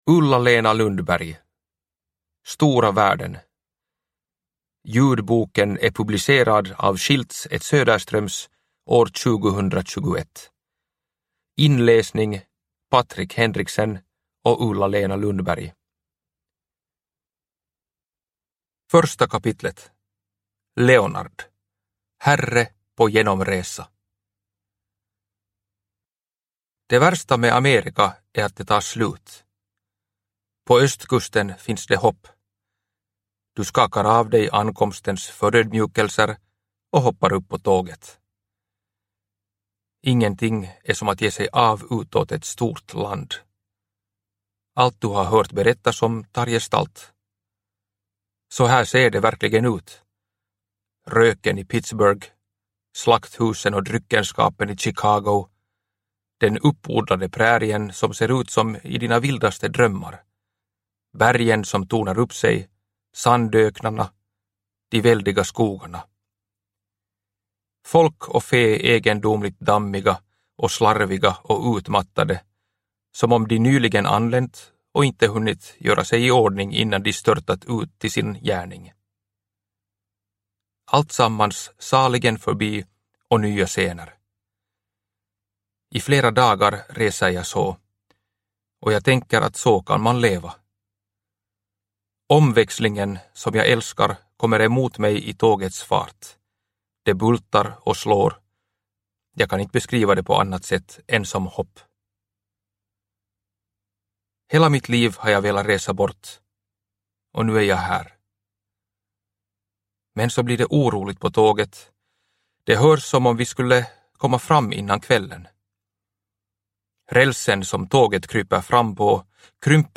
Stora världen – Ljudbok – Laddas ner